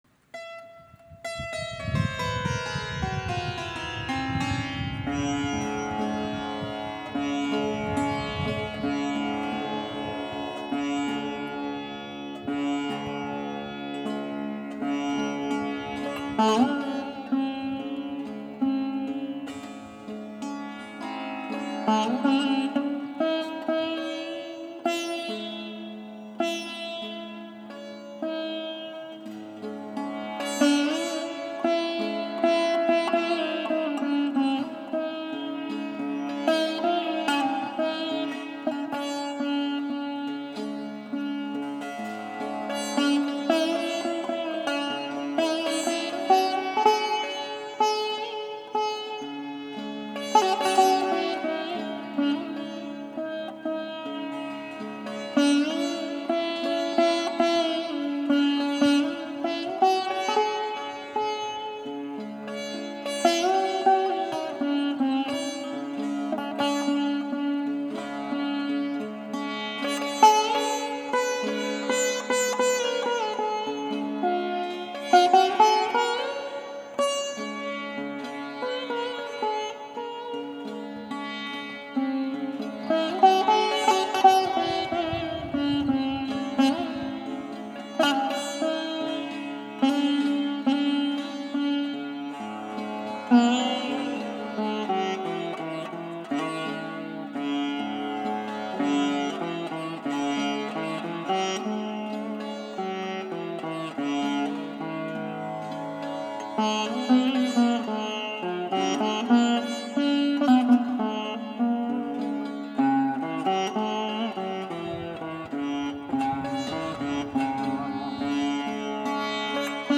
played on the sitar.
We hope you enjoy this meditative offering as much as we do.